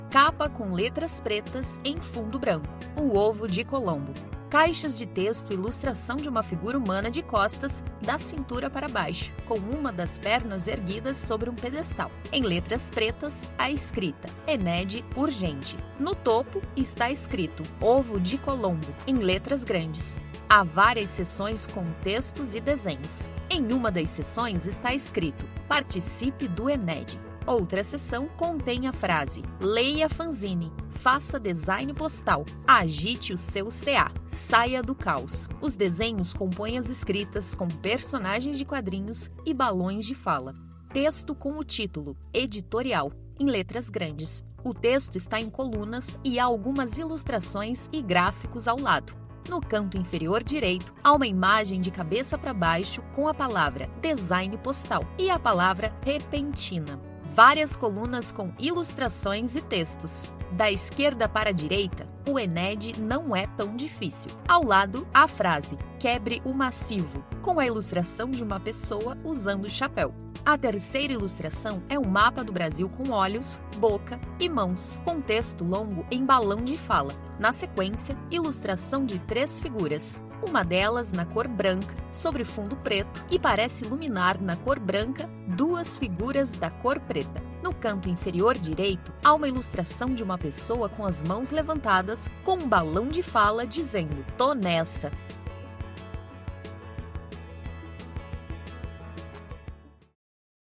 Audiodescrição do Fanzine n° 1